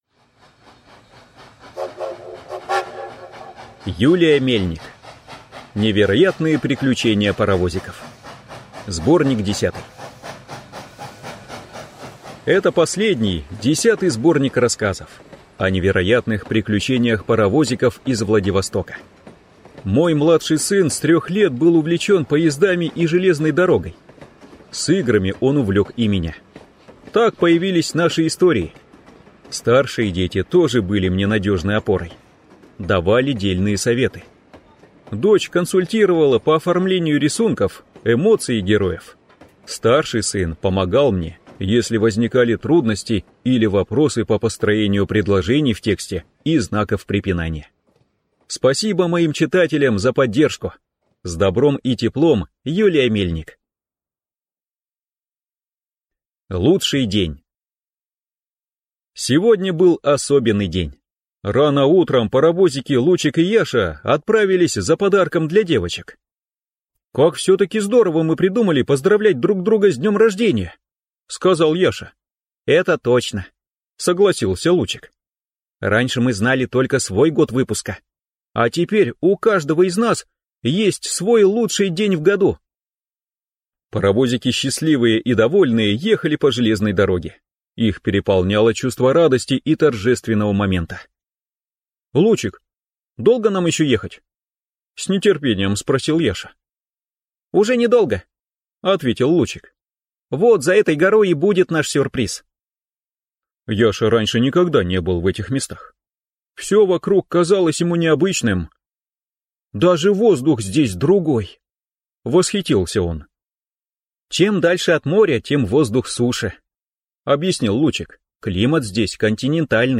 Аудиокнига Невероятные приключения паровозиков. Сборник 10 | Библиотека аудиокниг